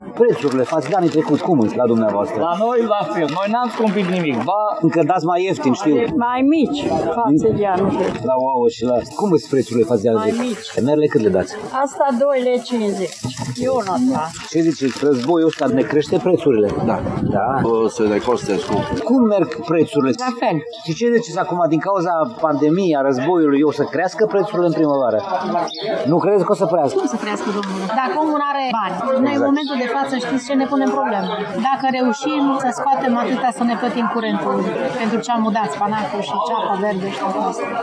Producătorii care vând în Piața agroalimentară ”Cuza Vodă” din Tg. Mureș spun că nu au crescut prețurile față de anul trecut, fiindcă lumea nu are bani.